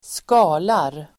Uttal: [²sk'a:lar]